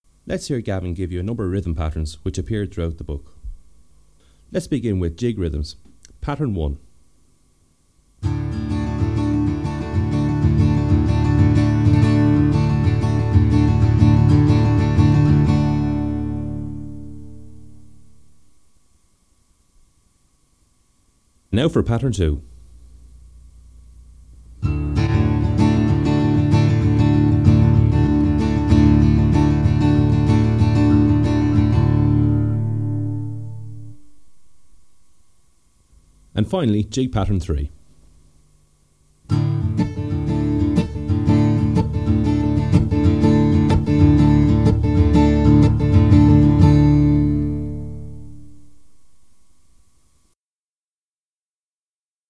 Jigs.mp3